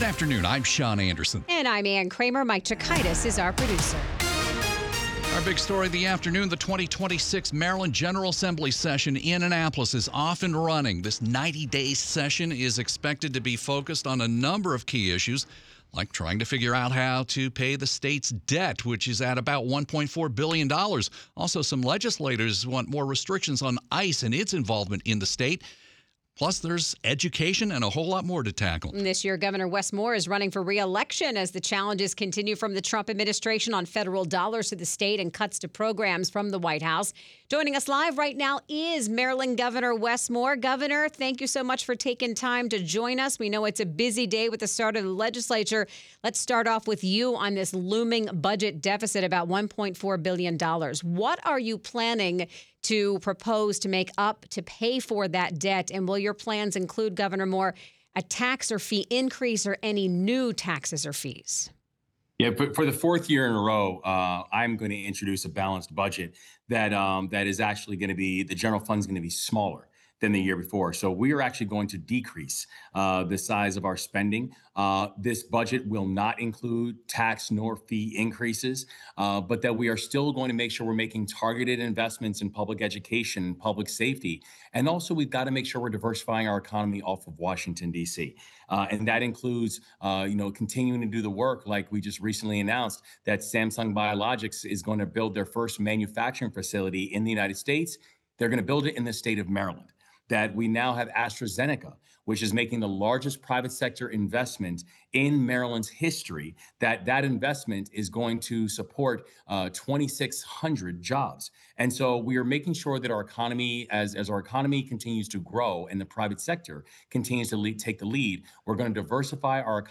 Maryland Gov. Wes Moore speaks with WTOP about his last legislative session during his first term as governor